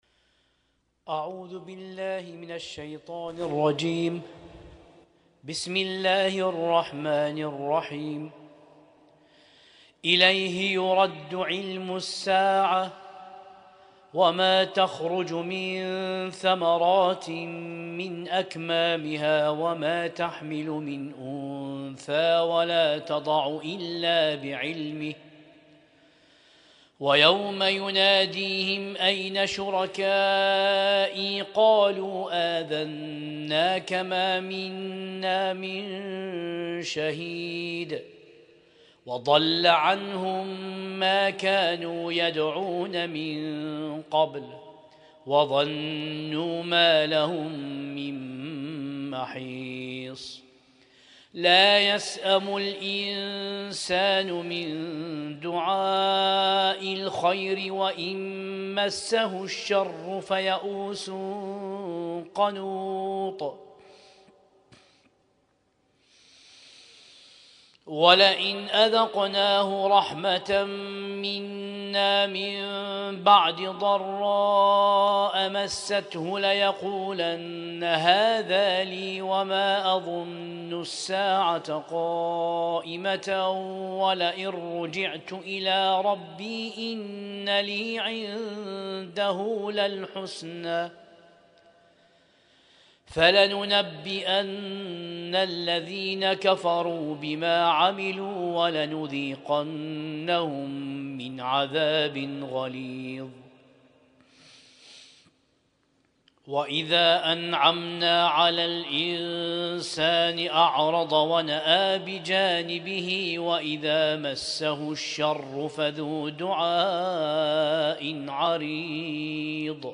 Husainyt Alnoor Rumaithiya Kuwait
القارئ: القارئ